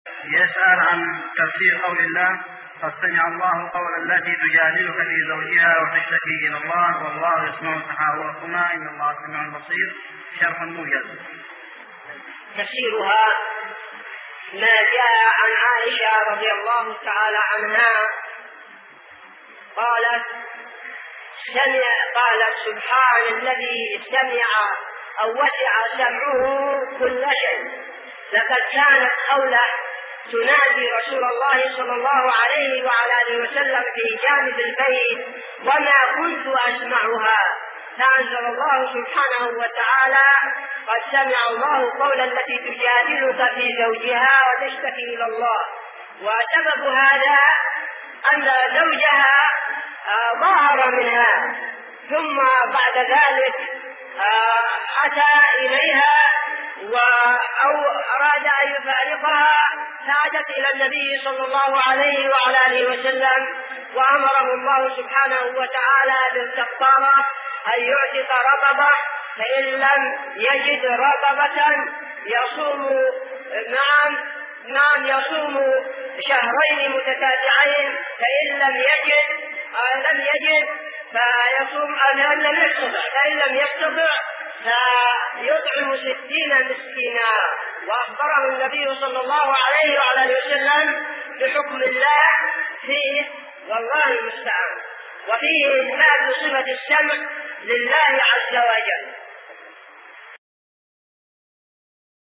------------- من شريط : ( أسئلة إذاعة الحديدة )